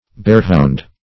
bearhound - definition of bearhound - synonyms, pronunciation, spelling from Free Dictionary
Bearhound \Bear"hound`\, n. A hound for baiting or hunting bears.